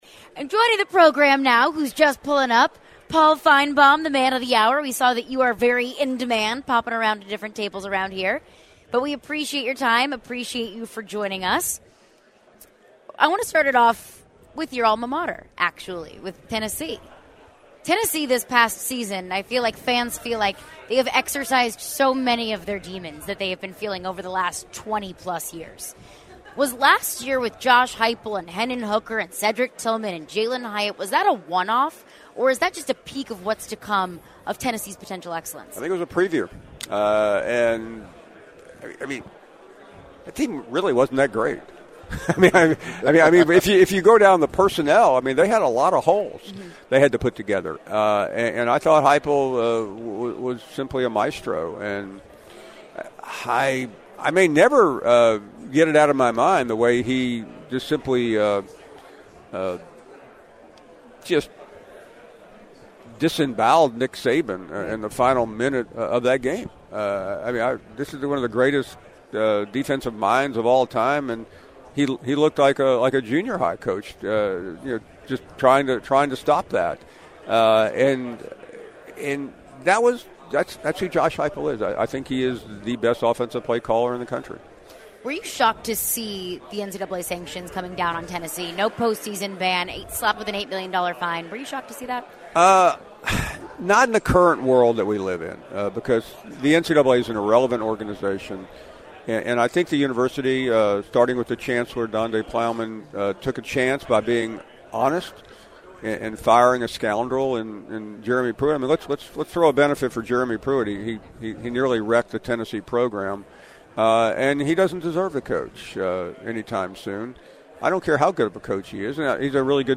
Paul Finebaum Interview (7-18-23)